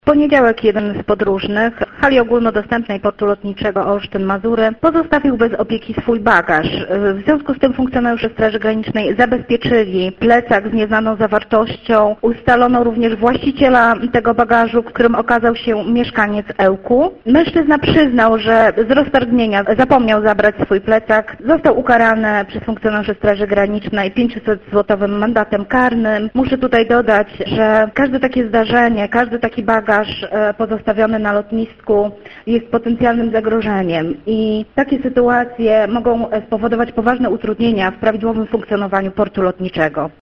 O poważnych konsekwencjach pozornie błahej sytuacji mówi sierż. SG